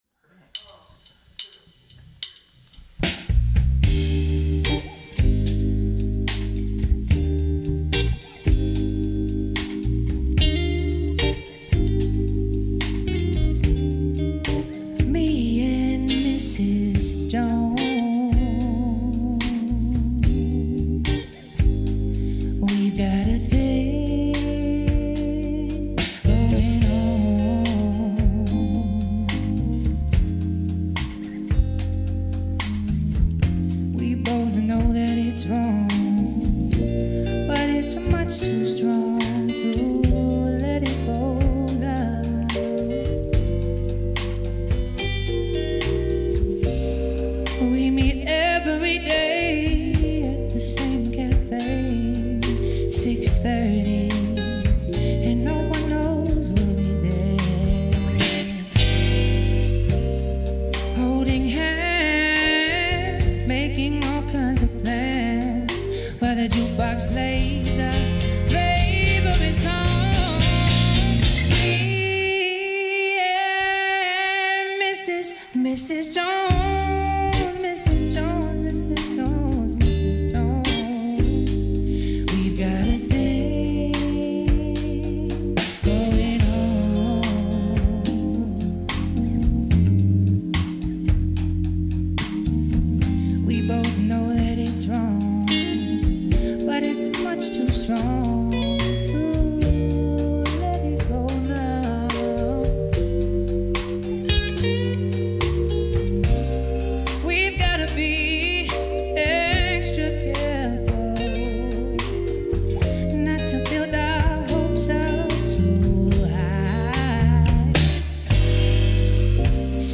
Pjesme su u LIVE izvedbi, u realnim atmosferama.